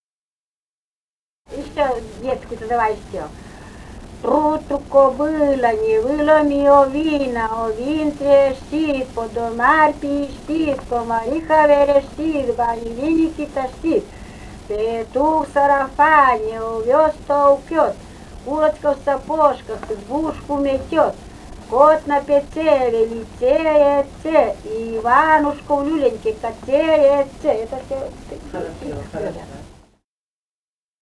«Тпру, тпру кобыла» (детская прибаутка).